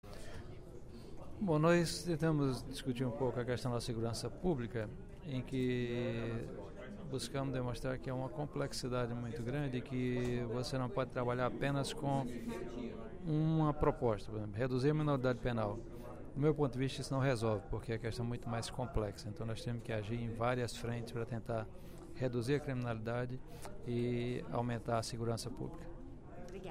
Em pronunciamento durante o primeiro expediente da sessão plenária desta sexta-feira (07/11), o deputado Professor Pinheiro (PT) voltou a questionar a questão da redução da maioridade penal.